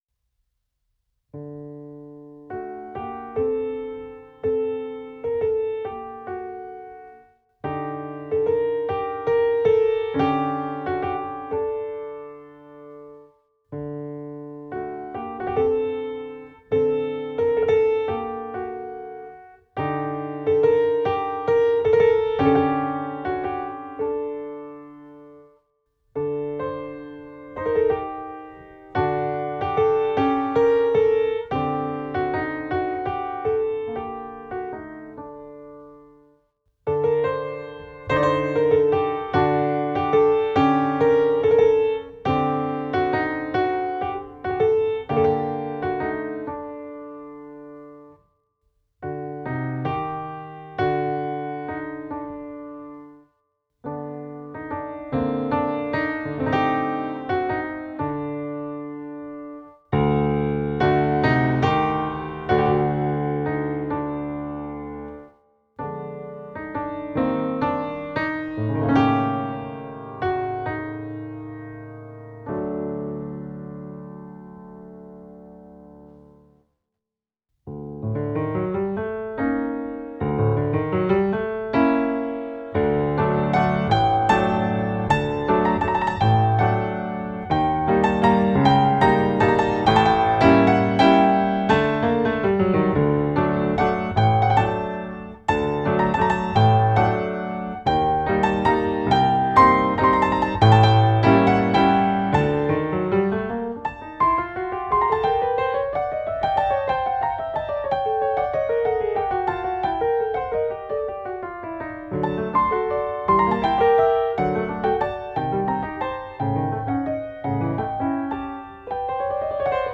Recorded in 2005, USA.
Inspired by a Persian folk song